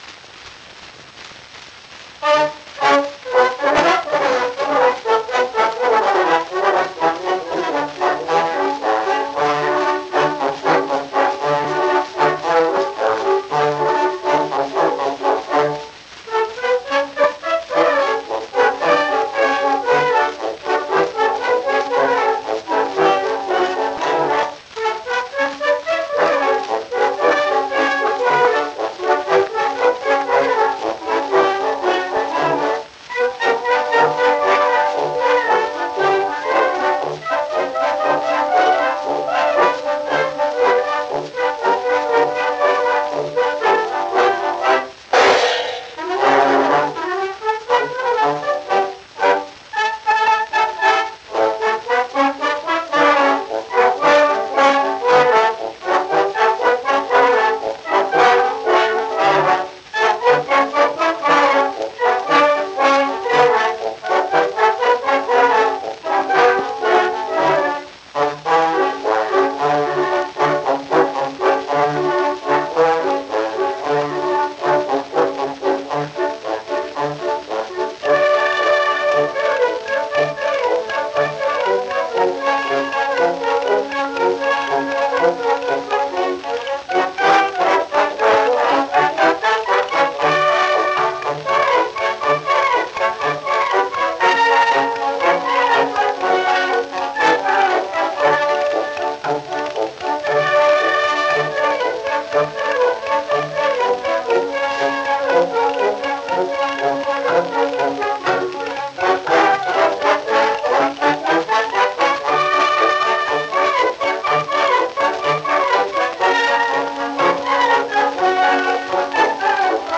Sie spielten bei der Aufnahme typischerweise in gleichmäßig hoher Lautstärke.
London Military Band: La Mattchiche (Petro Badia, Charles Borel-Clerc).